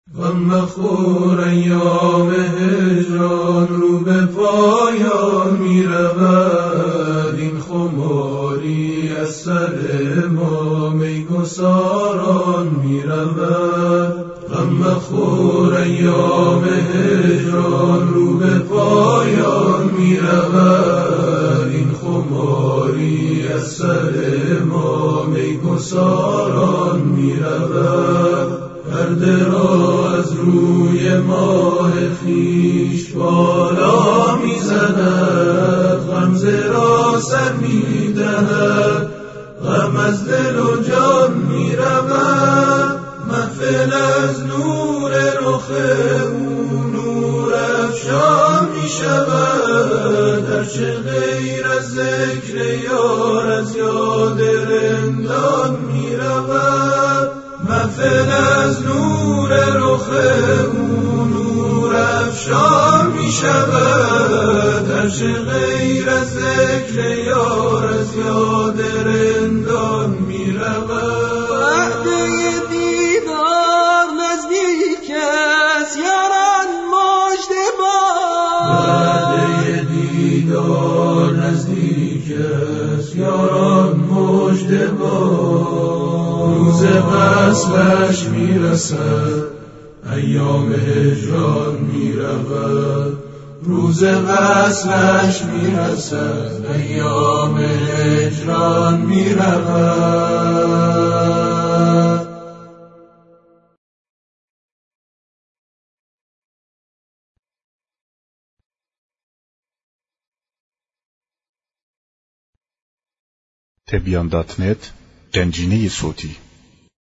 همخوانی مهدوی